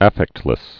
(ăffĕktlĭs)